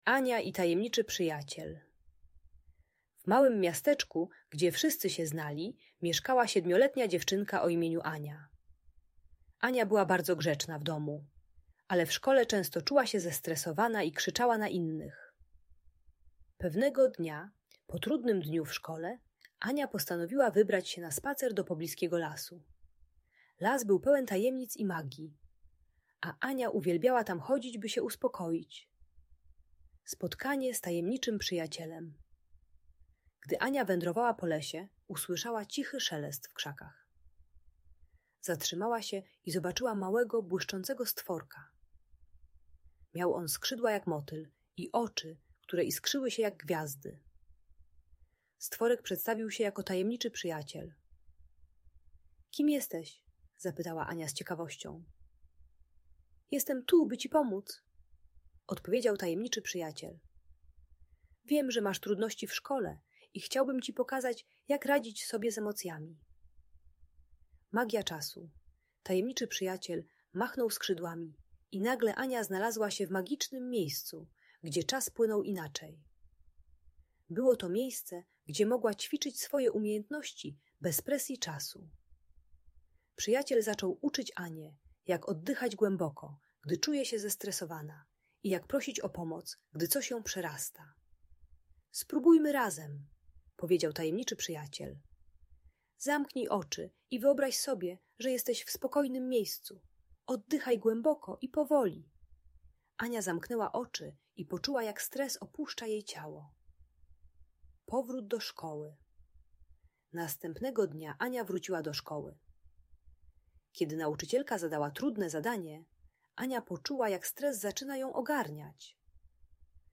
Ania i Tajemniczy Przyjaciel - Szkoła | Audiobajka